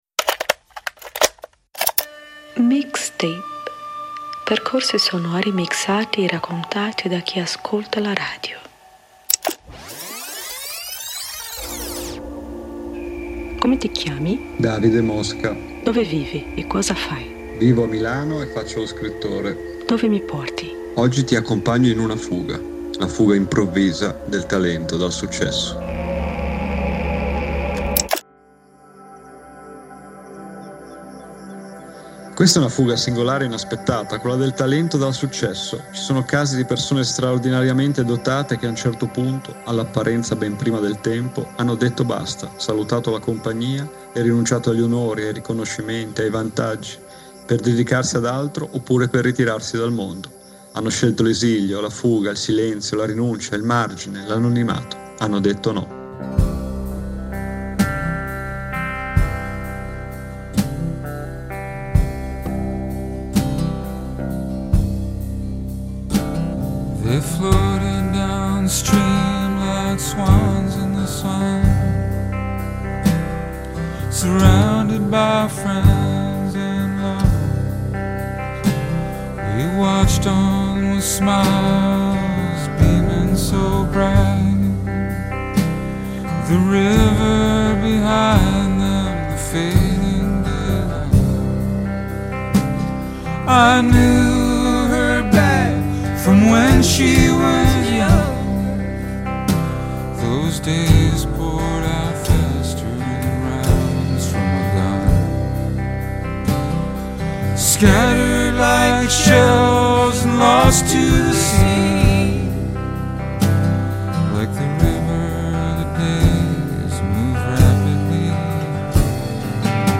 Anche in questo caso si tratta di uno svolgimento non lineare né tanto meno atteso: la fuga dell’artista talentuoso dalla dote in cui eccelle. Persone straordinariamente abili che a un certo punto hanno detto basta, per dedicarsi ad altro oppure ritirarsi dal mondo. A far da contrappunto tra queste vicende, un’avvincente scelta di brani indie.